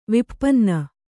♪ vippanna